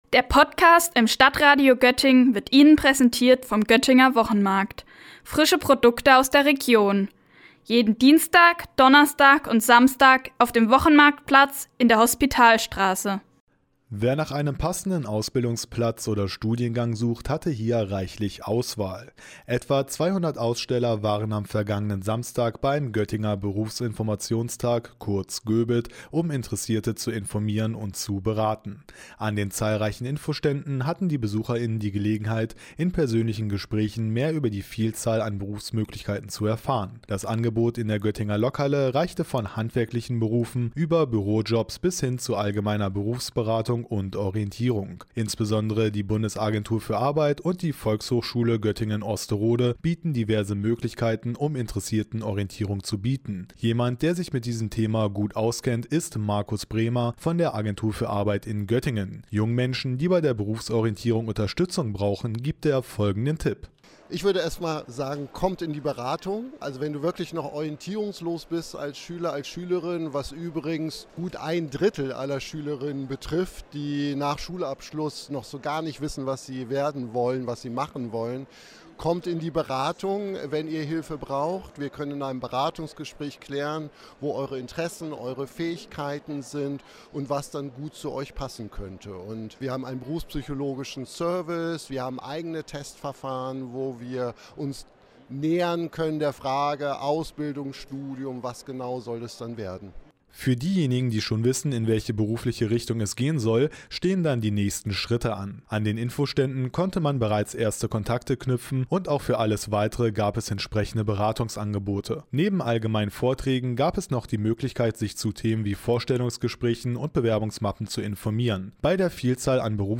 Für sie und viele weitere war der GöBit genau der richtige Ort, um mehr über die Vielzahl an Berufsmöglichkeiten und entsprechende Beratungsangebote zu erfahren. Das StadtRadio hat den Aktionstag mit einer Sondersendung begleitet.